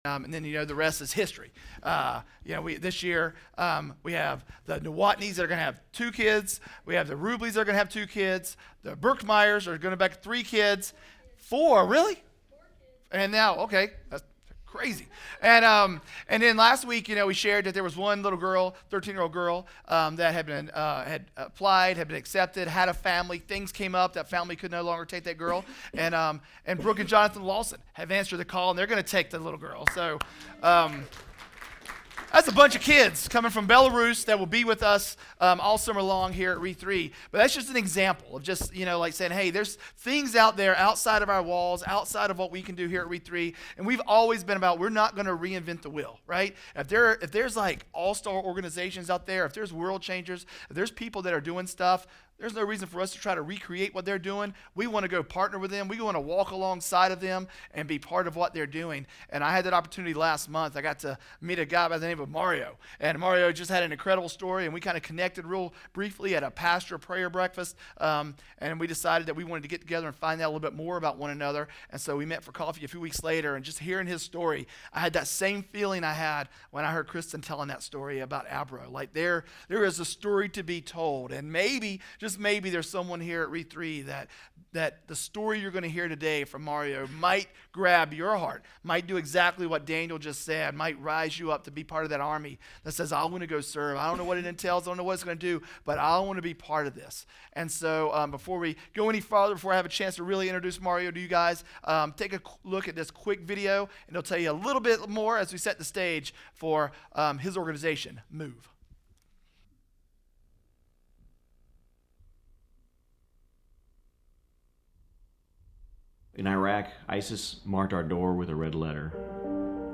Project:Re3 RE3 Sermon Audio